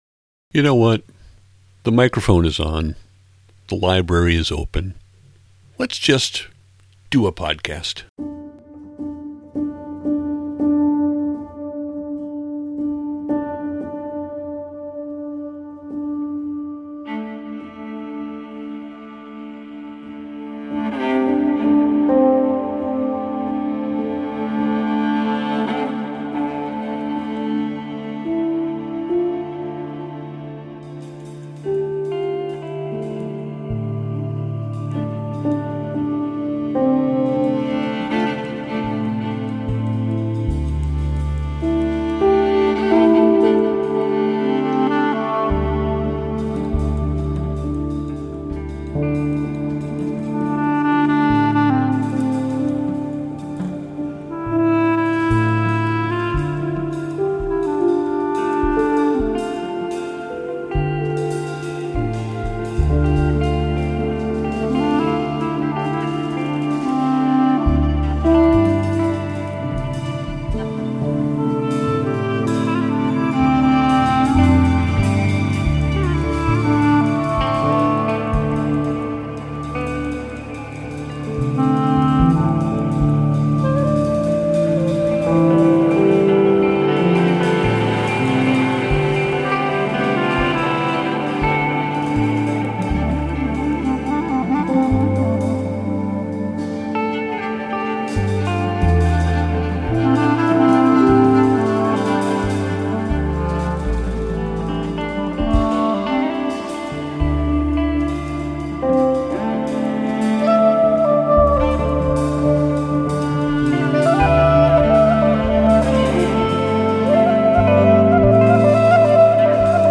And once again I spoil you with a non-stop ride.